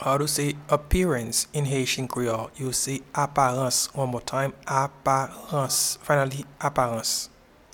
Pronunciation and Transcript:
Appearance-in-Haitian-Creole-Aparans.mp3